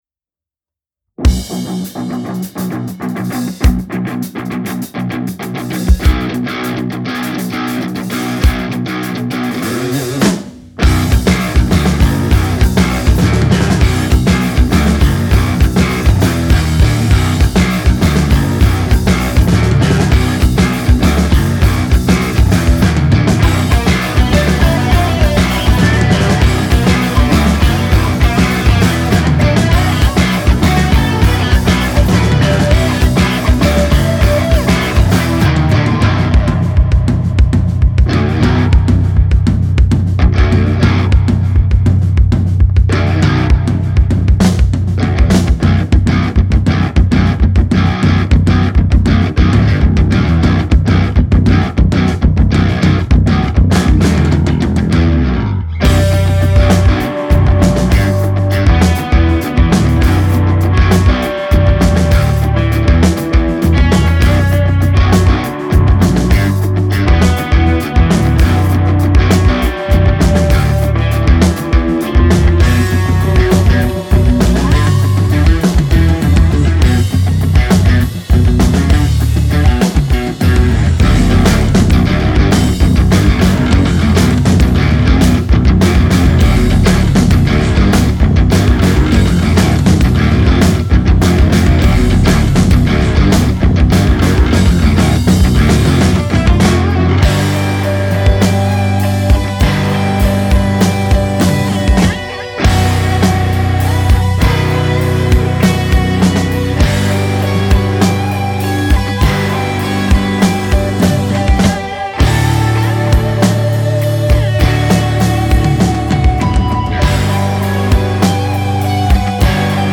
Записывал не я, только сведение и мастеринг.